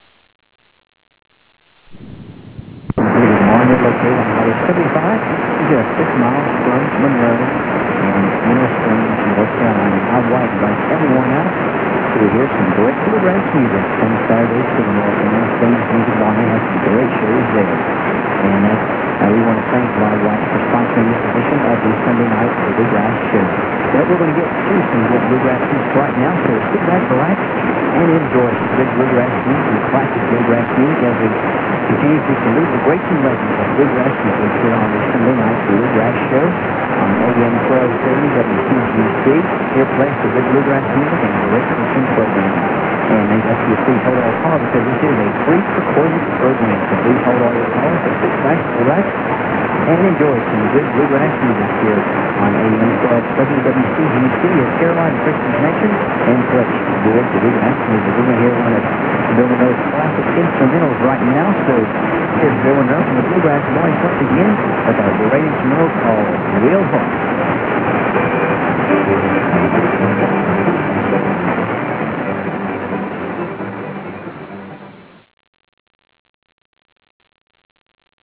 heavy auroral activity brought this awesome 10 KW North Carolina Catch in!